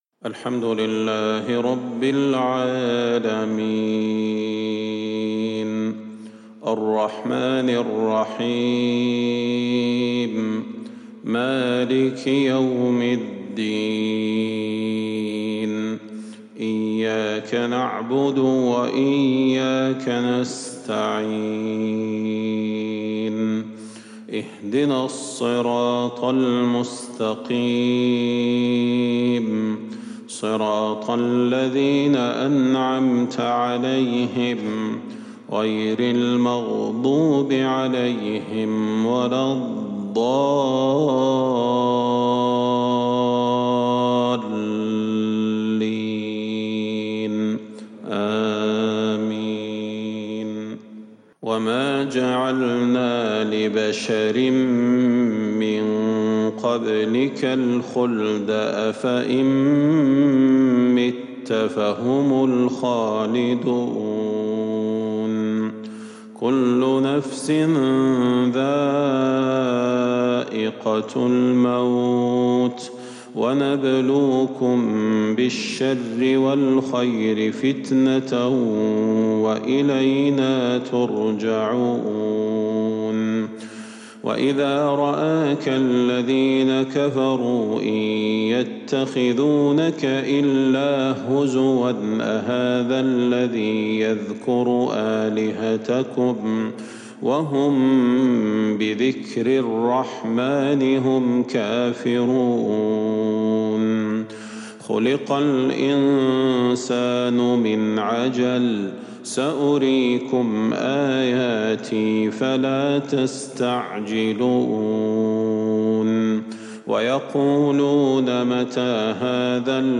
صلاة الفجر للقارئ صلاح البدير 25 ربيع الآخر 1442 هـ
تِلَاوَات الْحَرَمَيْن .